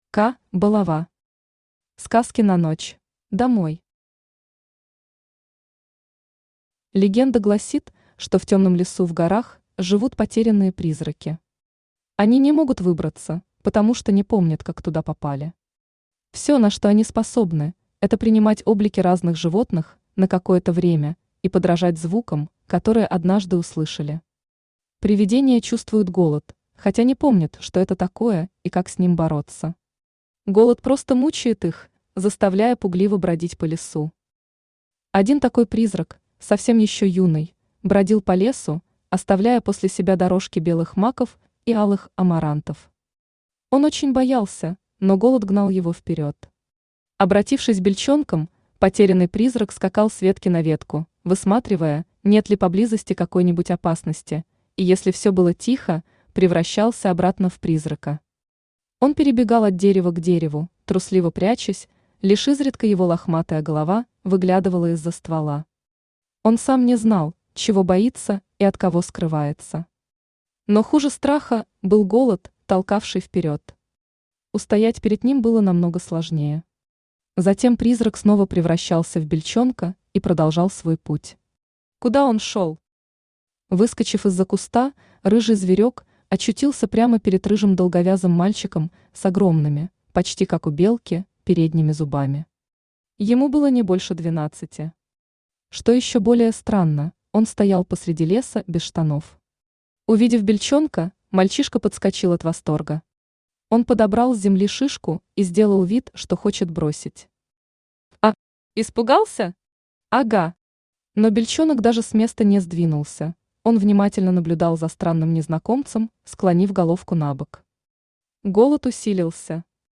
Аудиокнига Сказки на ночь | Библиотека аудиокниг
Aудиокнига Сказки на ночь Автор К. Балова Читает аудиокнигу Авточтец ЛитРес.